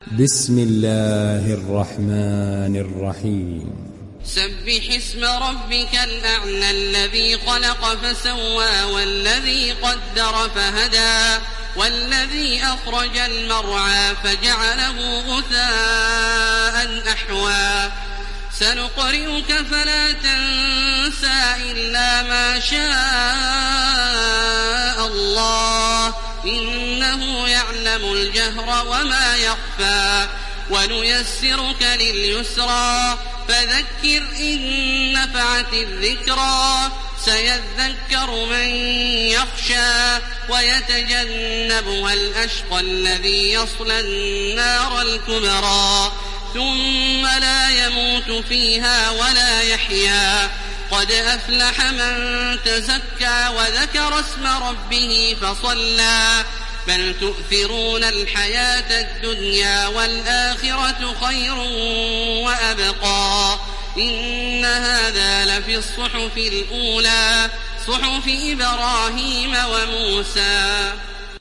Download Surat Al Ala Taraweeh Makkah 1430